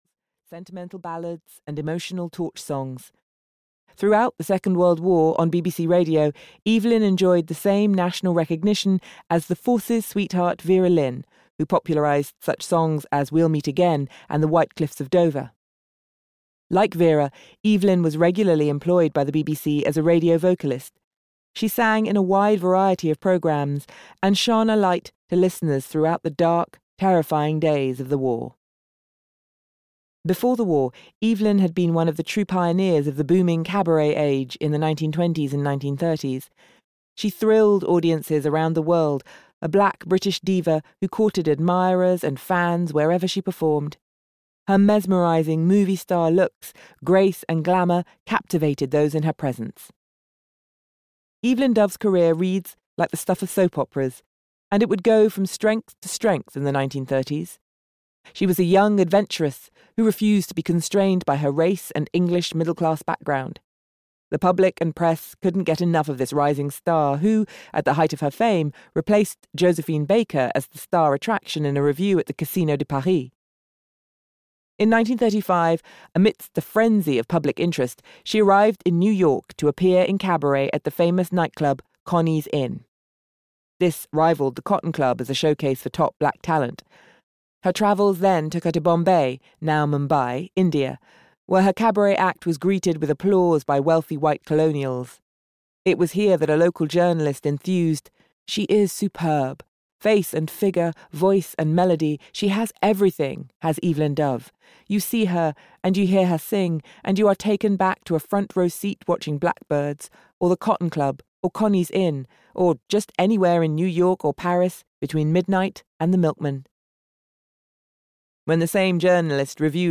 Audio knihaEvelyn Dove: Britain’s Black Cabaret Queen (EN)
Ukázka z knihy